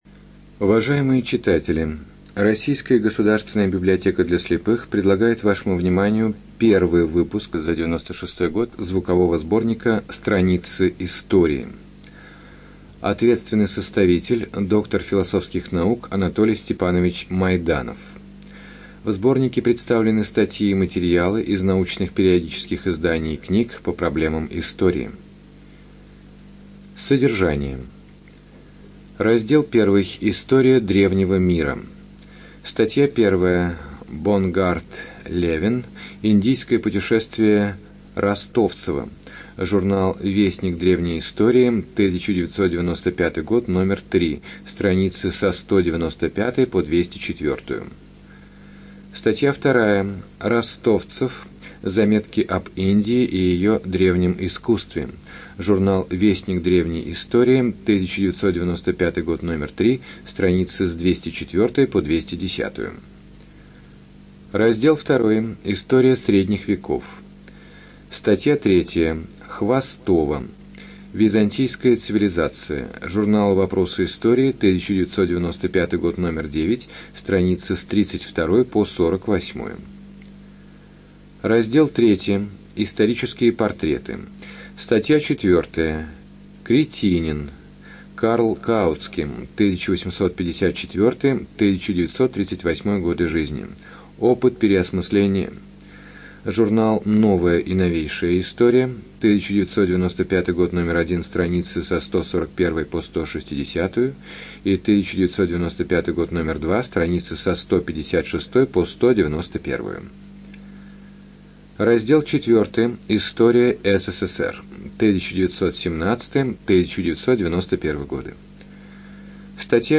Студия звукозаписиРоссийская государственная библиотека для слепых